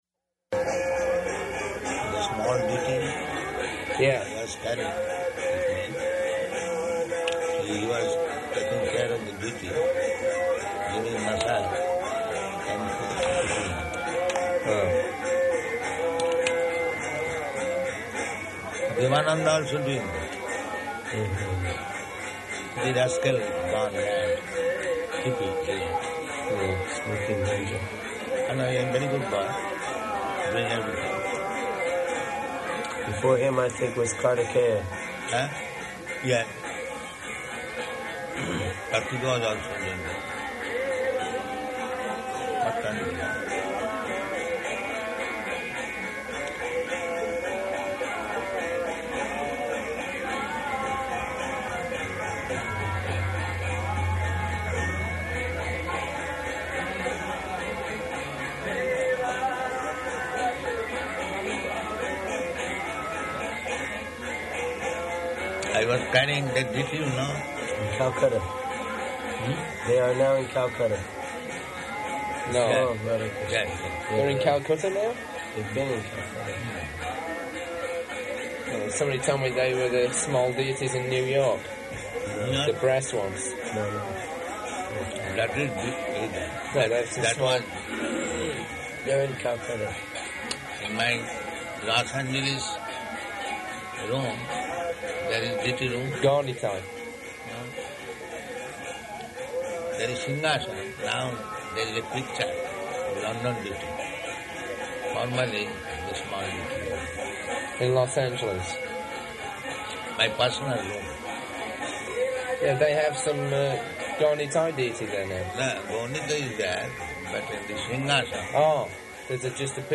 Room Conversation
Room Conversation --:-- --:-- Type: Conversation Dated: January 24th 1977 Location: Bhubaneswar Audio file: 770124R1.BHU.mp3 [loud kīrtana in background] Prabhupāda: The small Deity?